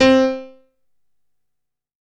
55ay-pno03-c3.wav